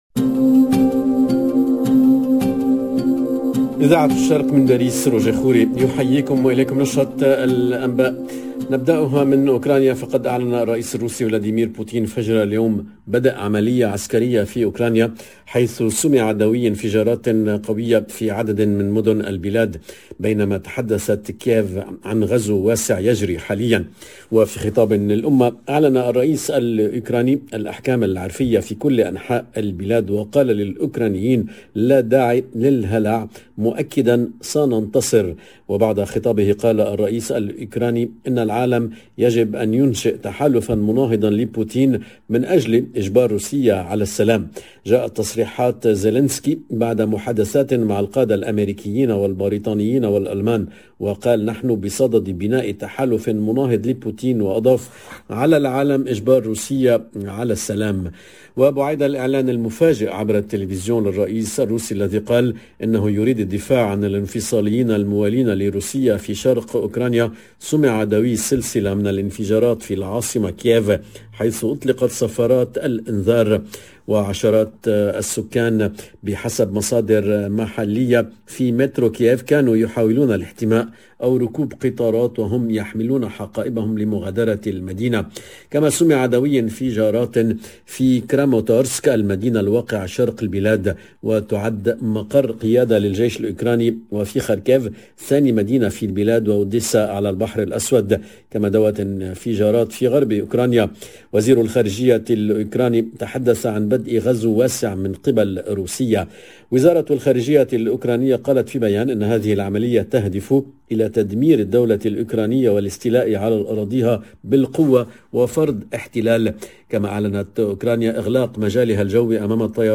LE JOURNAL DE MIDI 30 EN LANGUE ARABE DU 24/02/22